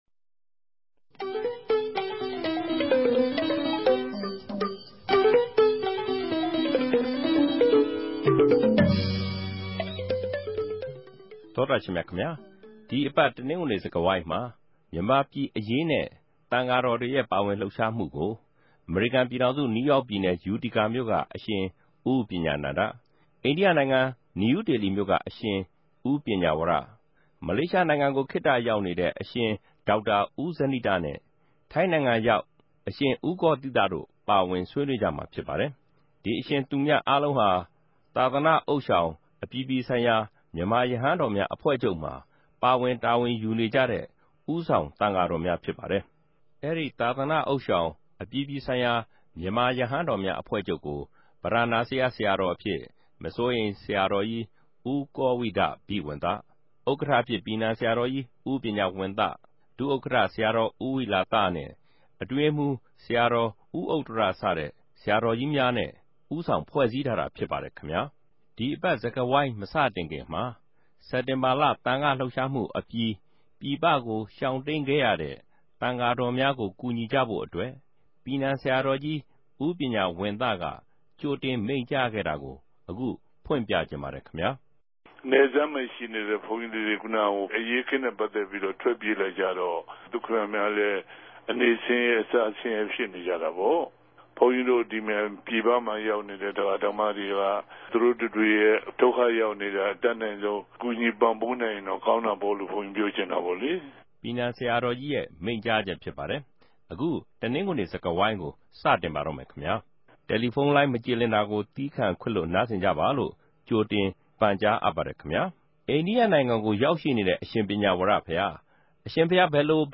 စကားဝိုင်း